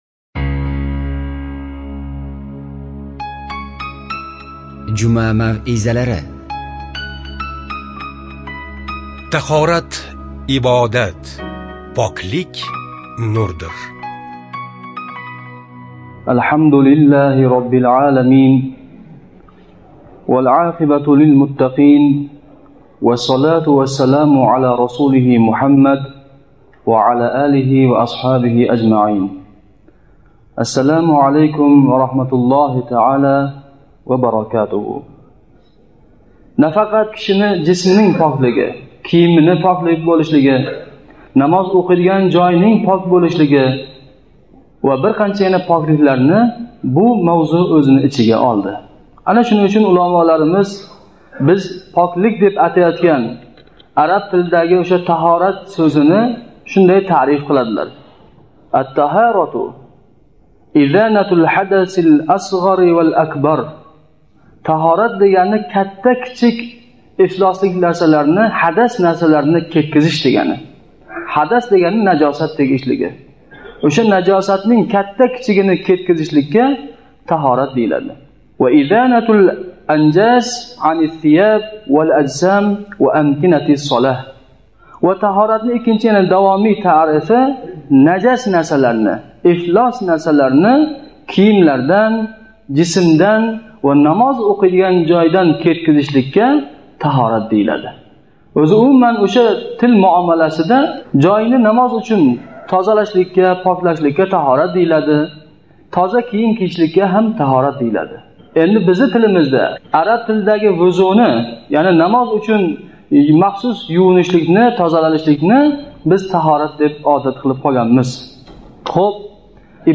Таҳорат ибодат, поклик нурдир Жума маърузалари